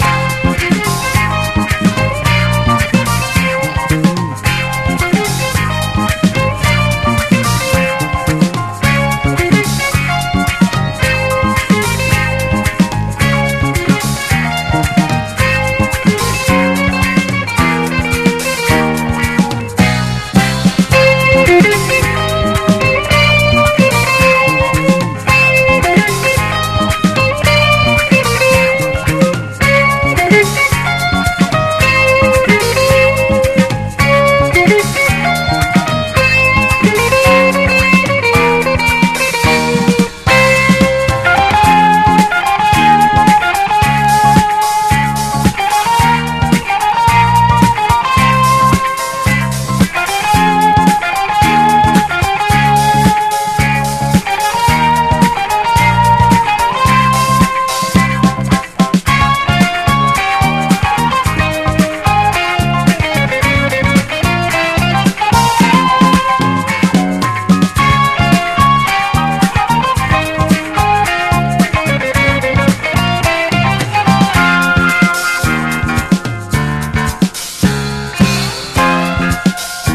JAPANESE GROOVE / DRUM / DRUM BREAK / JAPANESE JAZZ
民謡ジャズ・ファンクな強力トラックを収録！